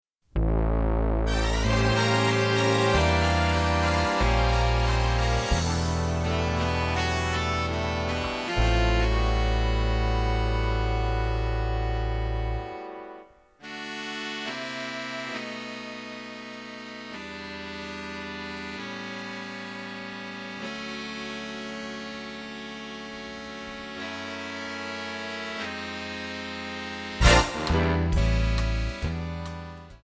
D
Backing track Karaoke
Jazz/Big Band, Duets, 1990s